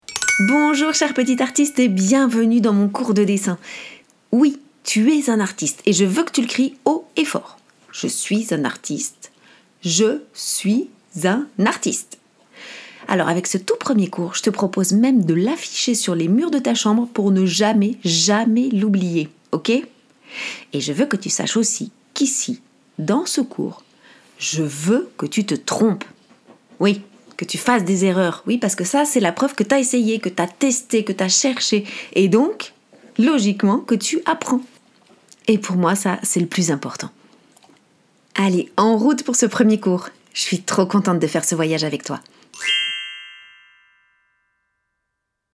• Une piste audio à lancer via QR code : je guide l’enfant tout du long.
Voici un exemple, un extrait d’un cours :